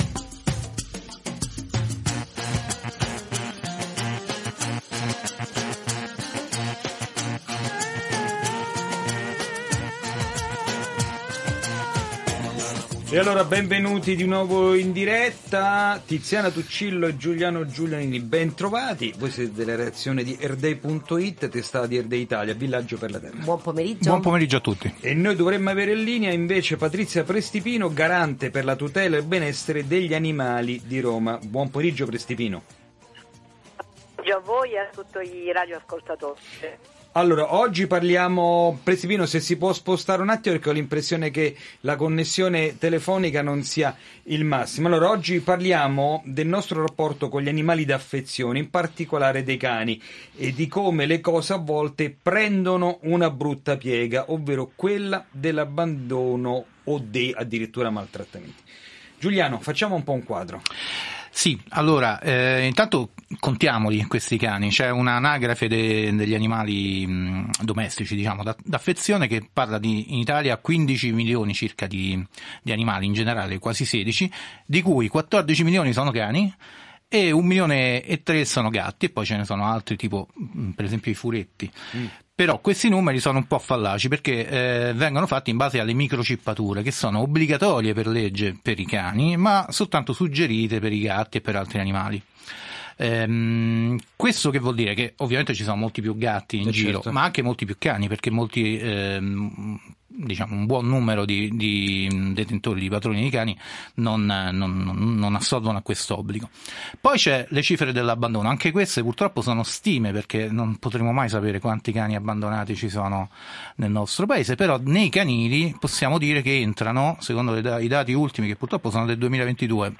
La situazione dei cani abbandonati e il sistema di canili pubblici che promuovono le adozioni, nella nuova puntata di Ecosistema; che questa settimana ha ospitato Patrizia Prestipino, Garante per la tutela e il benessere degli animali di Roma Capitale.